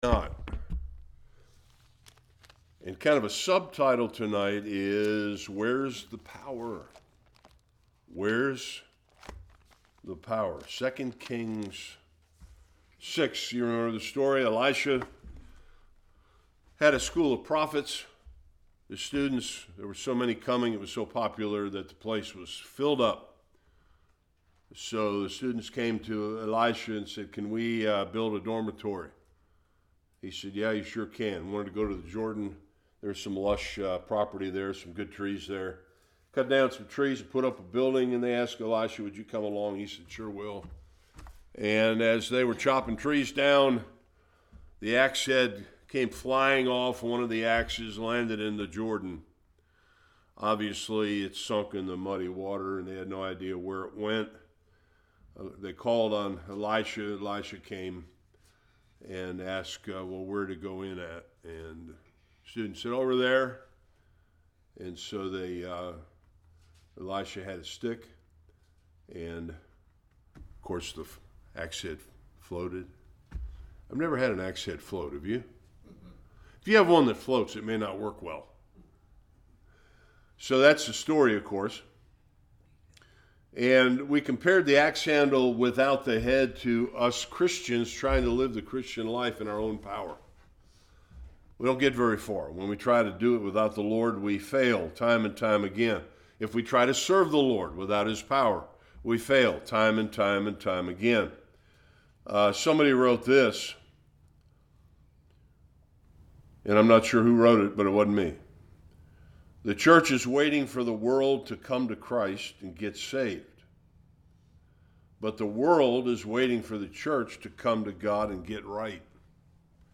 1-7 Service Type: Bible Study We will be making additional applications from this passage concerning why we don’t see revival.